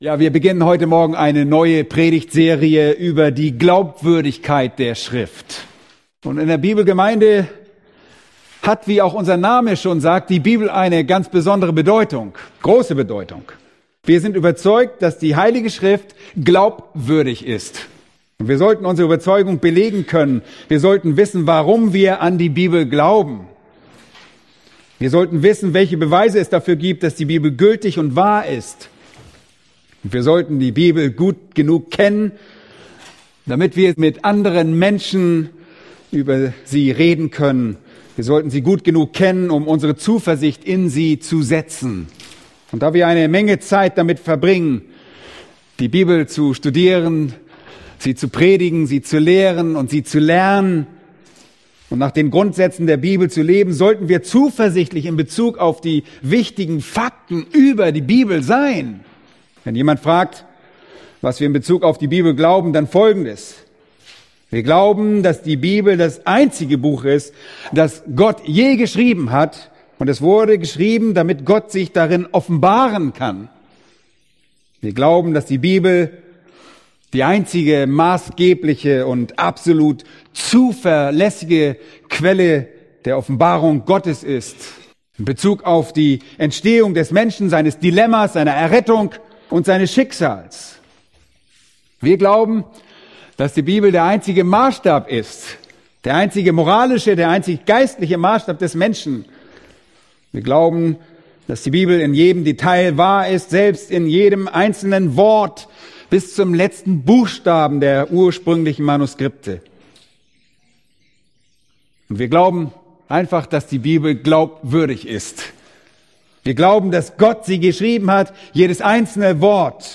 Diese adaptierte Predigtserie von John MacArthur ist genehmigt durch " Grace to You ".